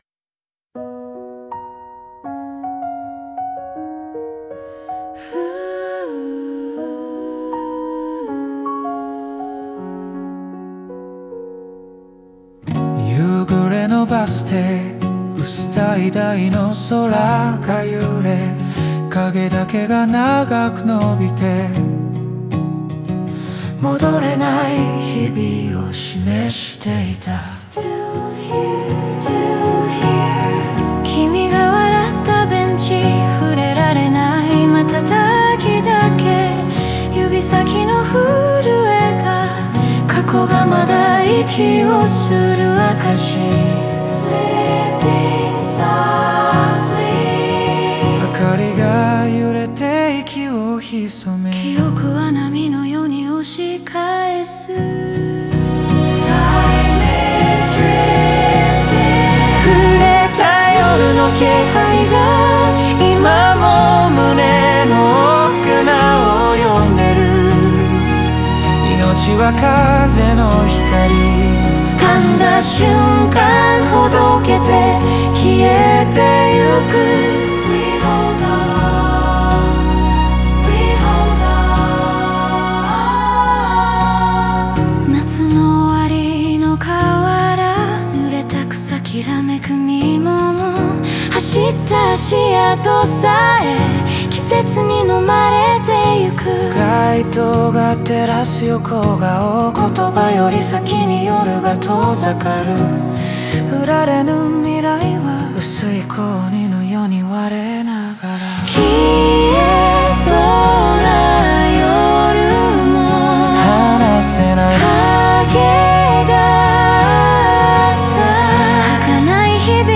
オリジナル曲♪
男女の声が交互に過去を回想し、冬の情景とともに残された痕跡の儚さが浮かび上がります。